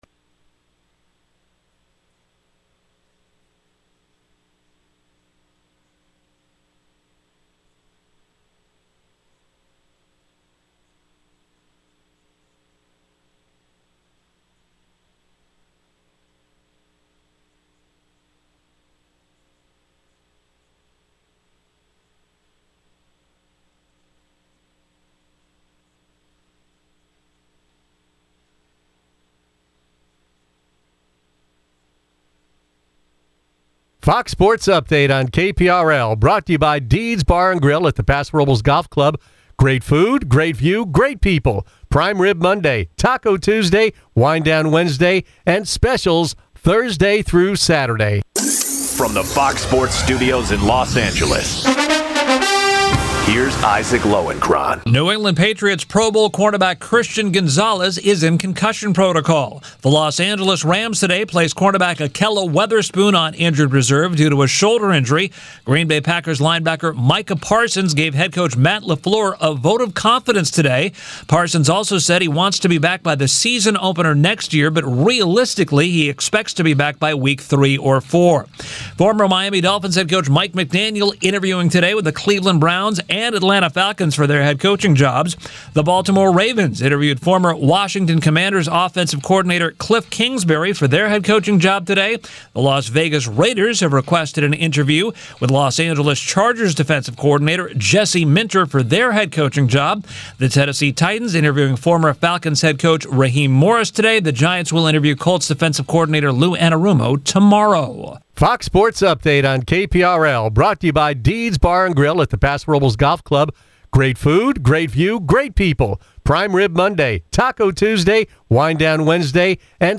Tune in to the longest running talk show on the Central Coast.